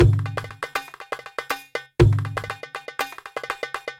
中东打击乐 105bpm
描述：阿拉伯打击乐循环...
Tag: 105 bpm Ethnic Loops Percussion Loops 787.54 KB wav Key : Unknown